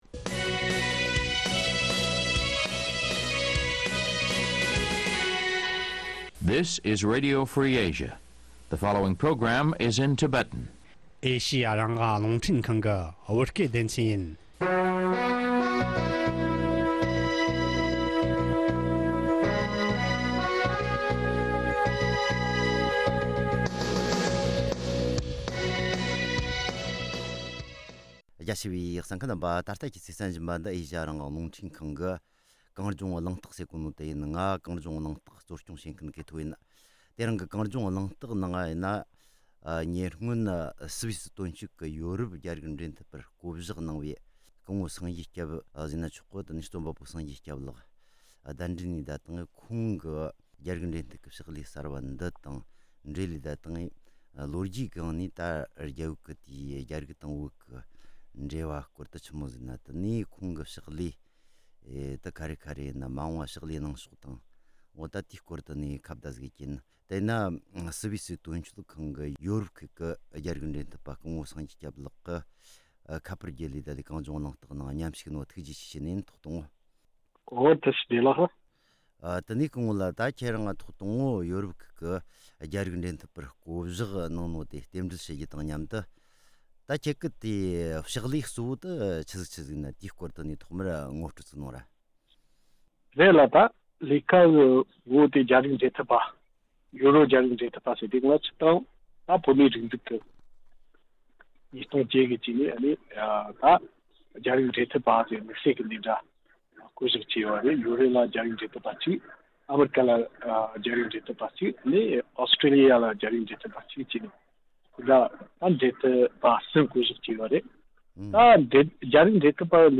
བཅར་འདྲི་ཞུས་པ་ཞིག